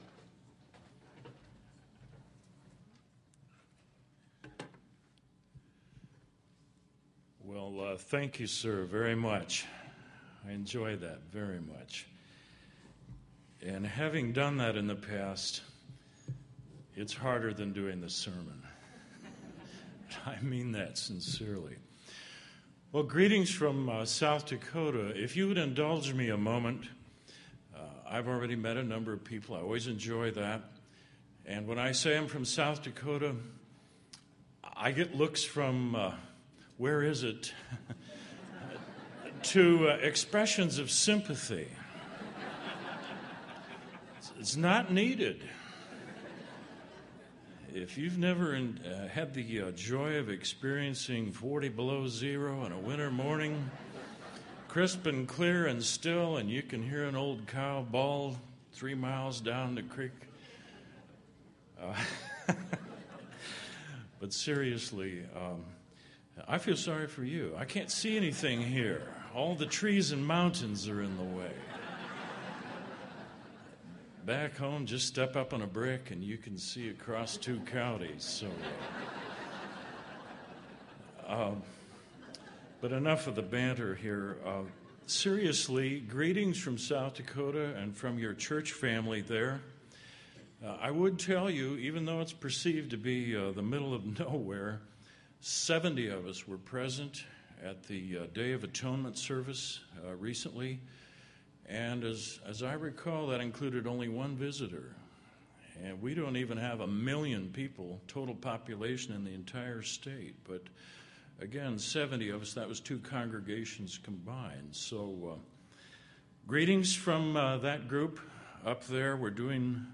This sermon was given at the Pigeon Forge, Tennessee 2014 Feast site.